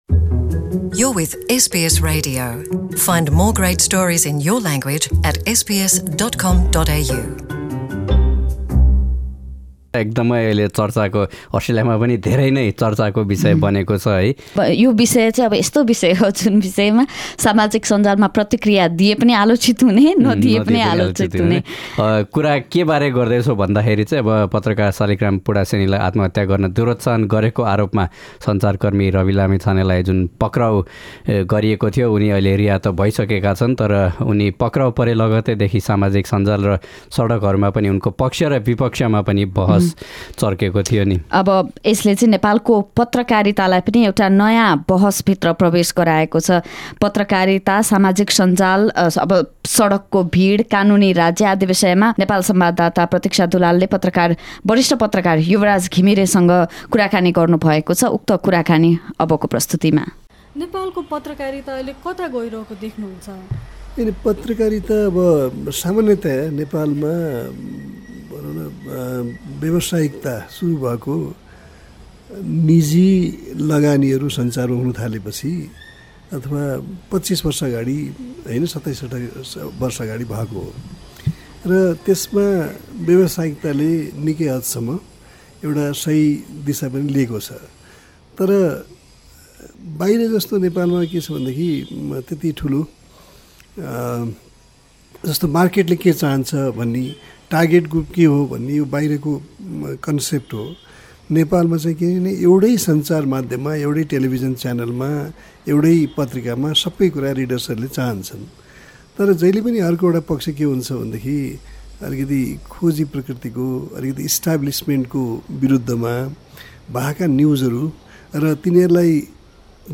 Source: facebook/ Yubaraj Ghimire वरिष्ठ पत्रकार युवराज घिमिरेसँग कुराकानी गरेका छौँ।